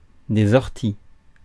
Ääntäminen
Ääntäminen Tuntematon aksentti: IPA: /ɔʁ.ti/ Haettu sana löytyi näillä lähdekielillä: ranska Käännöksiä ei löytynyt valitulle kohdekielelle. Orties on sanan ortie monikko.